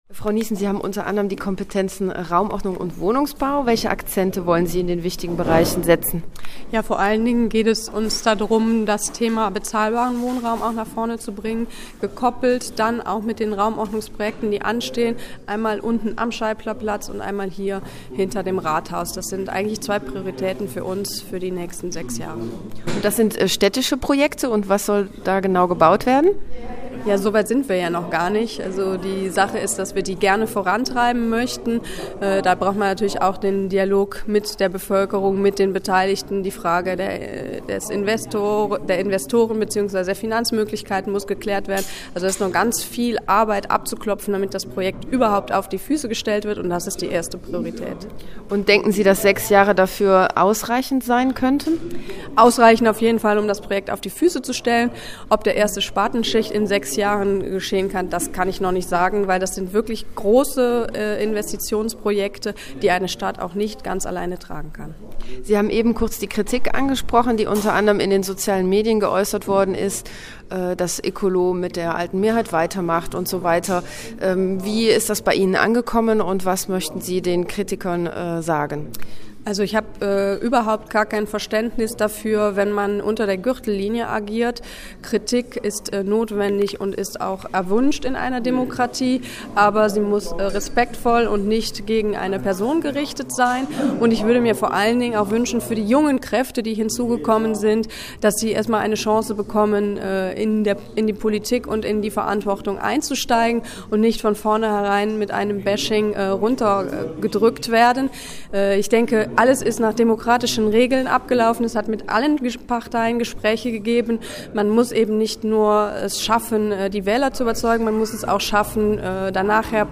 sprach zunächst mit der angehenden Bürgermeisterin: